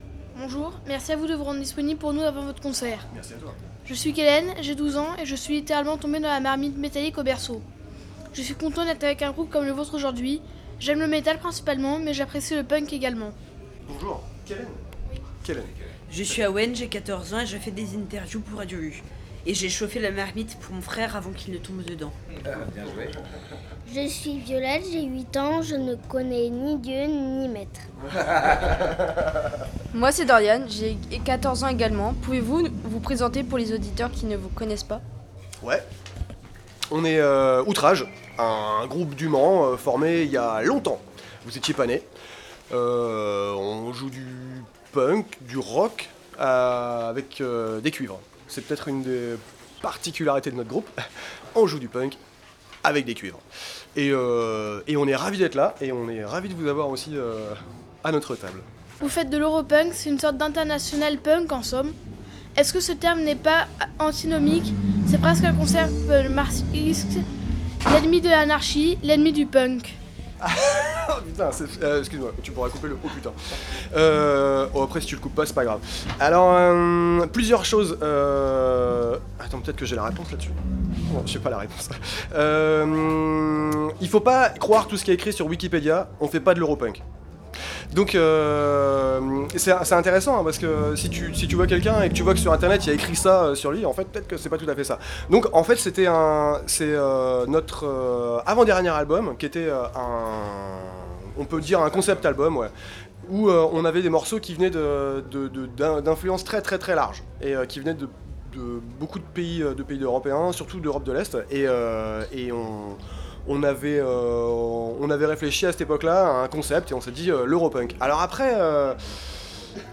solidarites_pleyben_interview_outrage.flac